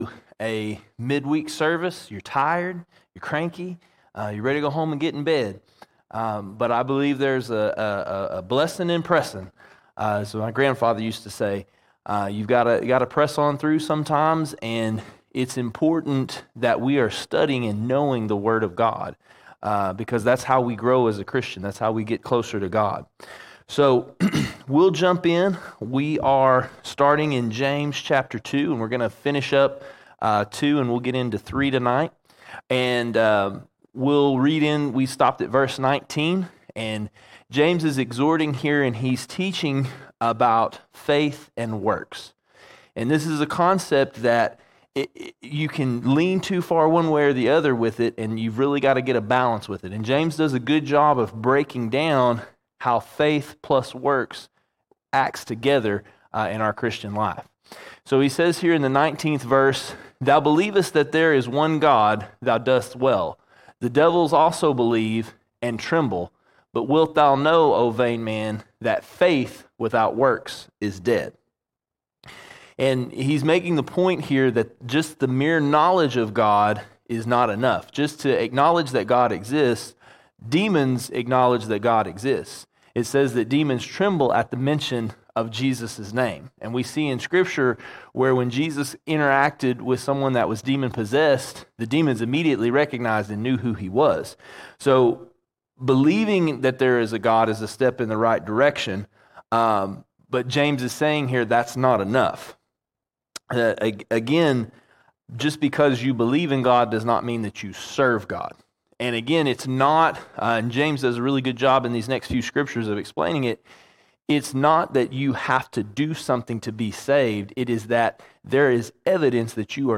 16 May 2024 Series: James All Sermons James 2:20 to 3:18 James 2:20 to 3:18 James continues teaching about faith without works is dead.